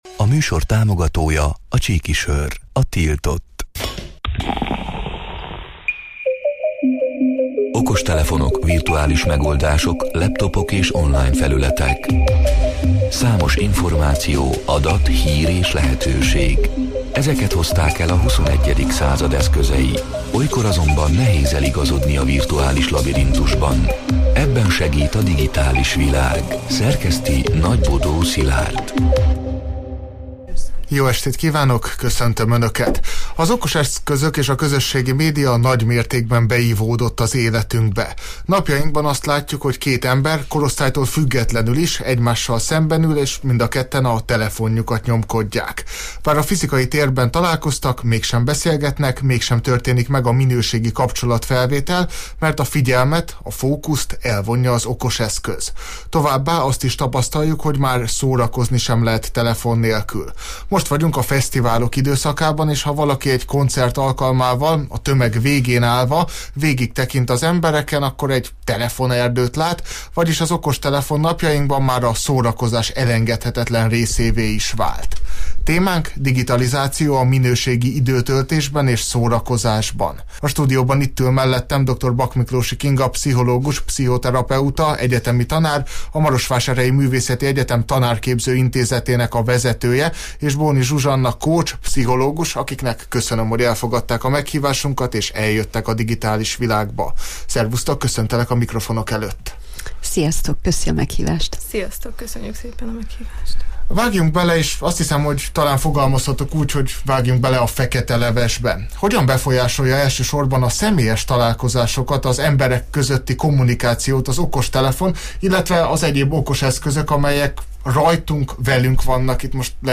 A Marosvásárhelyi Rádió Digitális Világ (elhangzott: 2025. július 15-én, kedden este nyolc órától élőben) c. műsorának hanganyaga: